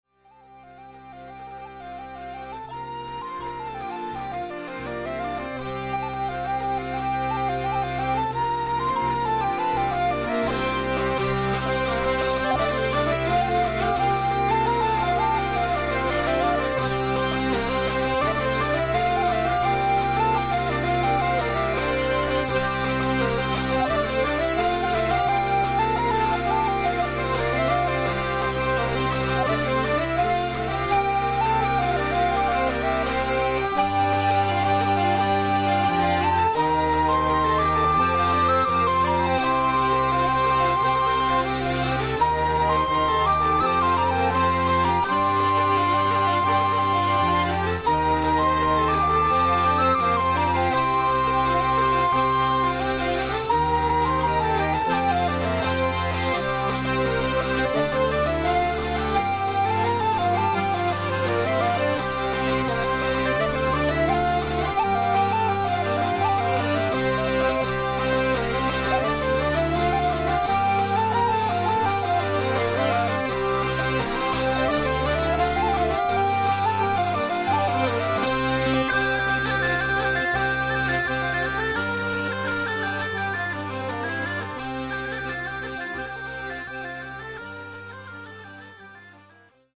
Scottishe